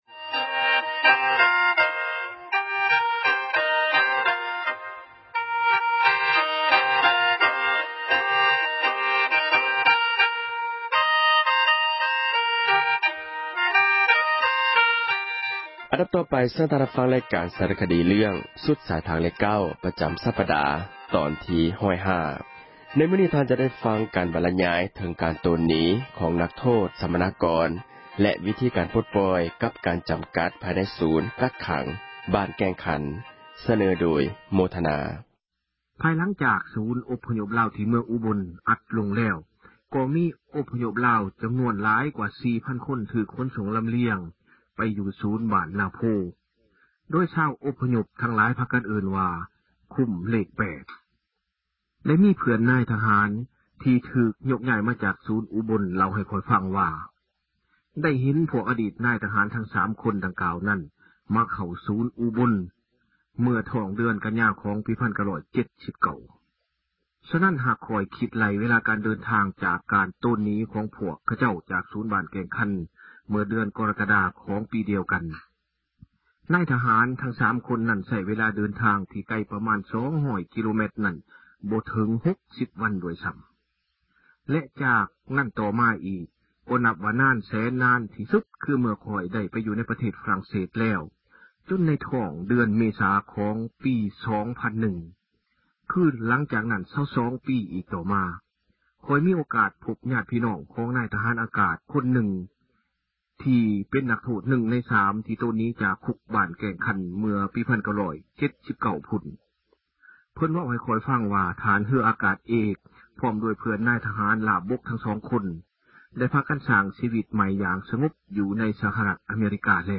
F-highway-nine-sunset ສາຣະຄະດີ ສຸດສາຍທາງເລຂເກົ້າ